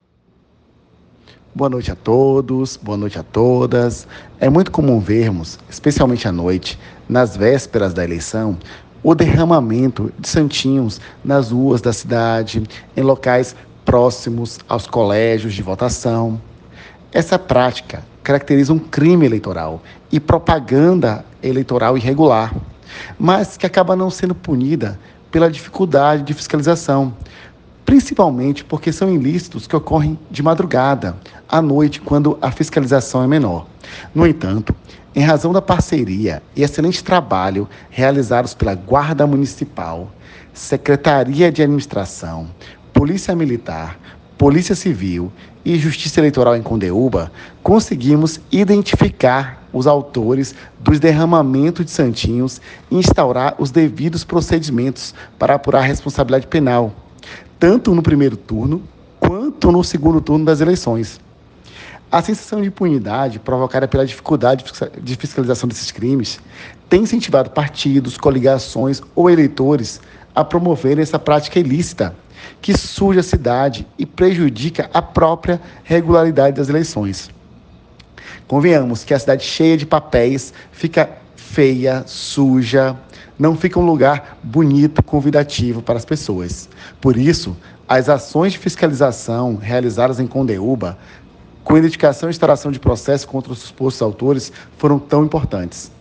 Ouça abaixo o áudio do Dr. Juíz Eleitoral
Juiz Eleitoral da 60ª ZE: Rodrigo Souza Britto